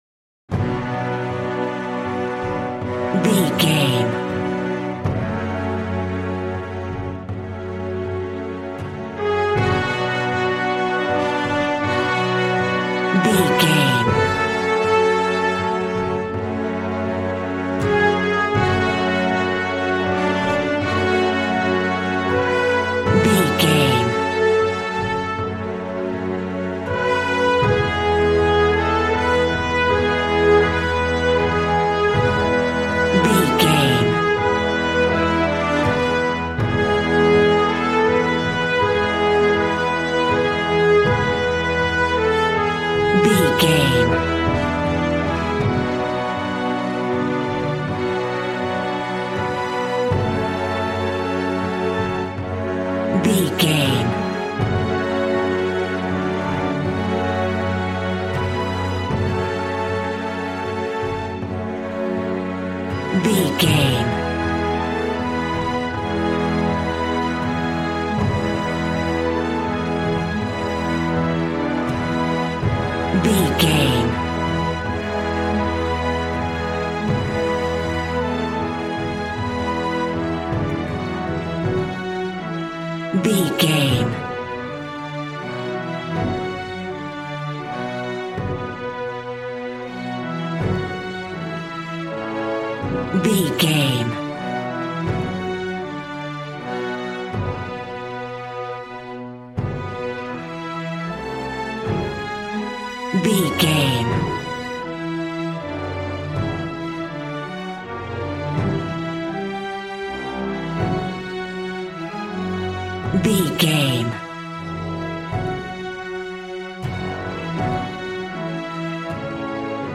Valiant and Triumphant music for Knights and Vikings.
Regal and romantic, a classy piece of classical music.
Ionian/Major
brass
strings
violin
regal